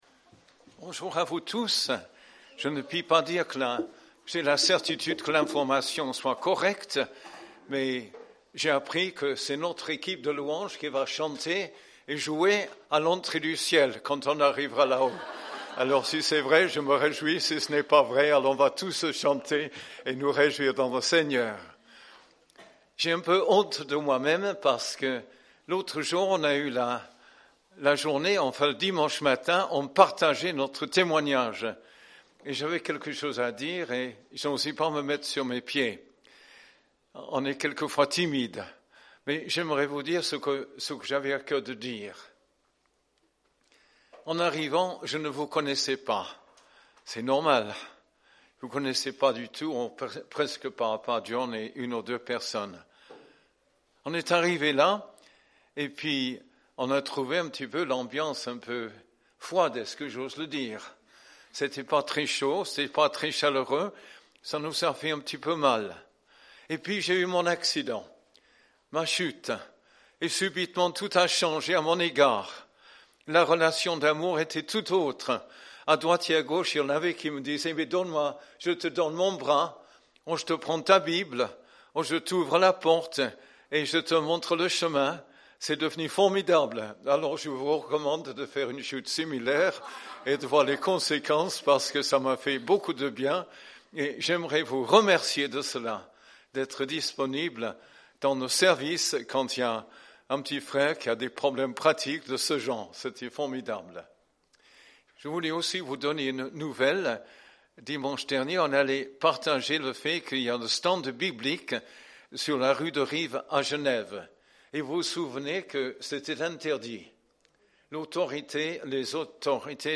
Culte du 13 décembre